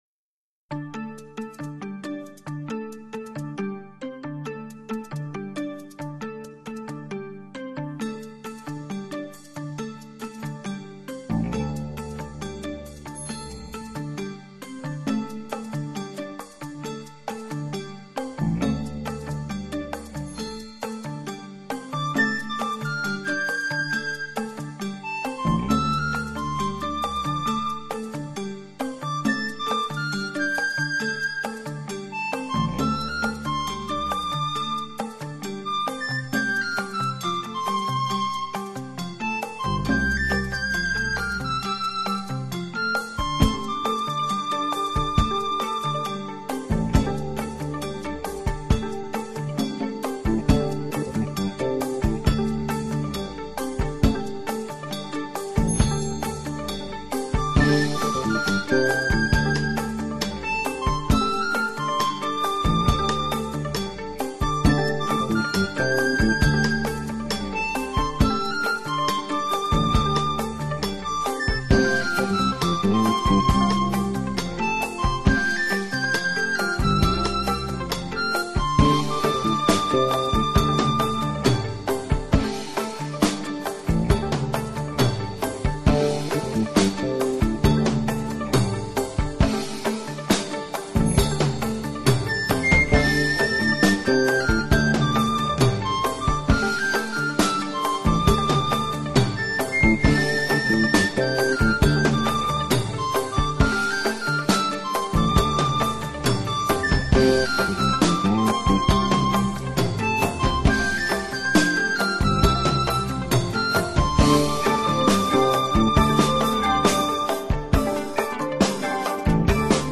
钢琴、吉他、弦乐、长笛等各式乐器